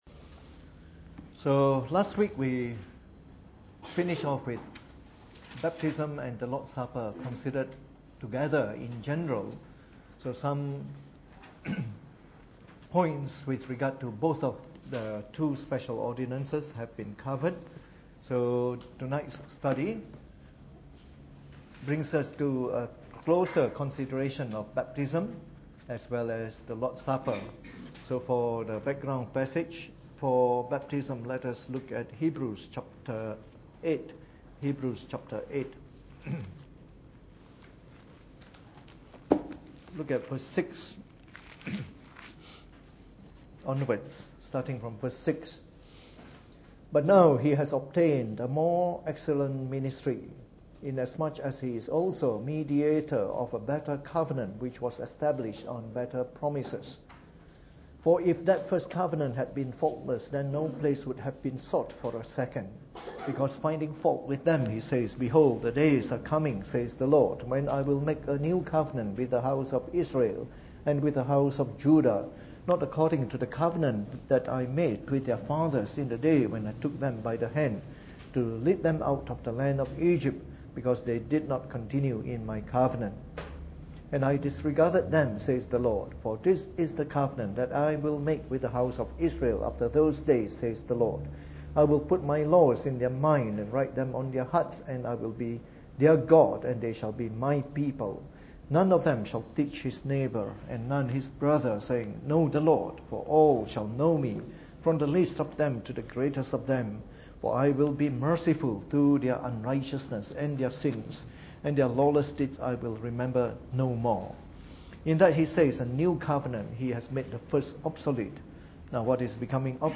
Preached on the 20th of July 2016 during the Bible Study, from our series on the Fundamentals of the Faith (following the 1689 Confession of Faith).